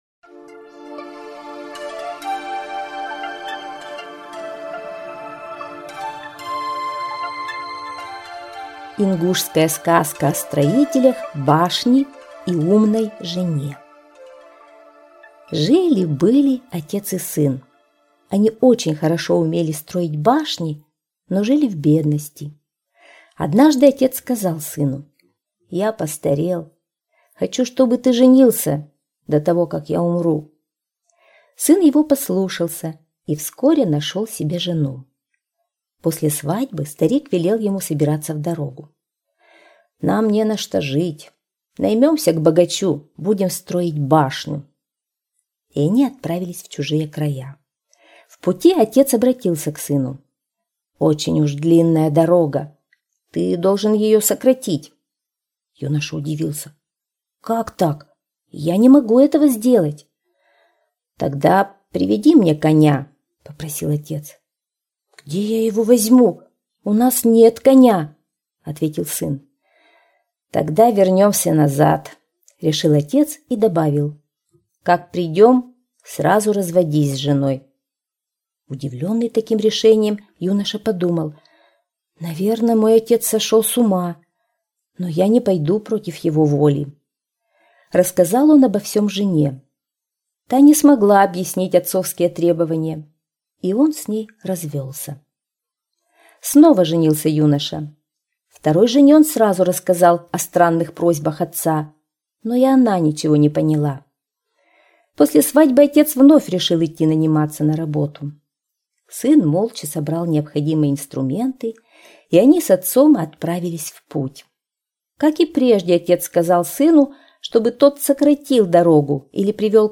Слушать ингушскую аудиосказку.